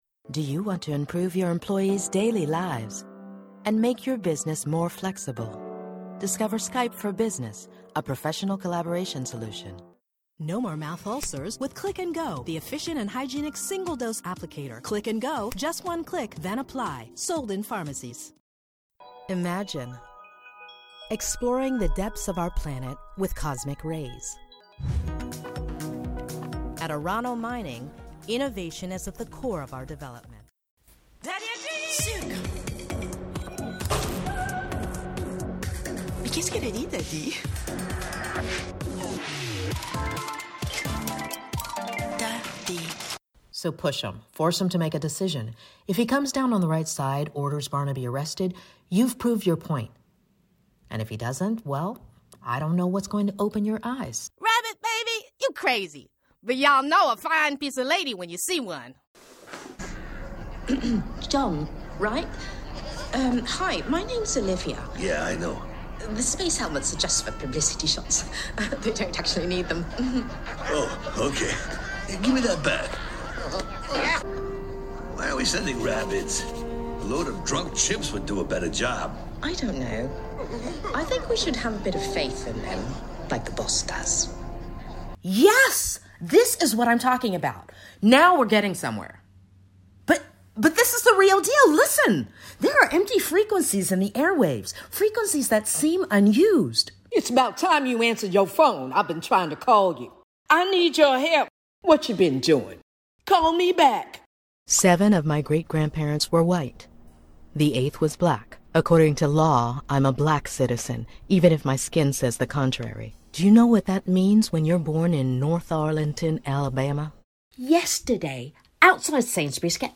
SPOKEN VOICE REEL 082024